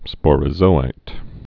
(spôrə-zōīt)